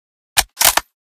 reload_start.ogg